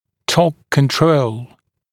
[tɔːk kən’trəul][то:к кэн’троул]контроль торка, управление торком